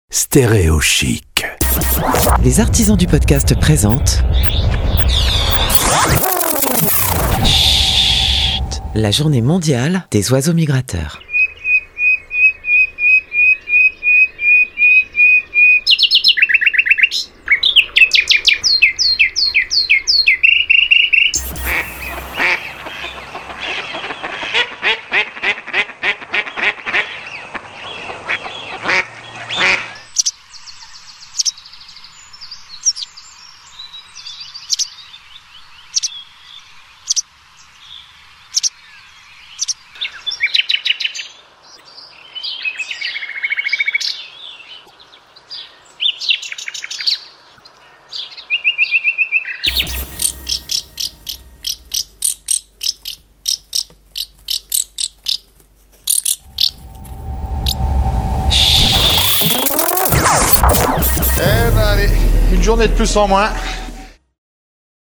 A l'occasion de la Journée Internationale des Oiseaux Migrateurs, le 8 Mail 2021, voici une immersion de 60 secondes avec Schhhhhht produit par les Artisans du Podcast.
Dans cet épisode : Colvert, Bergeronnettes, rossignol et hirondelles...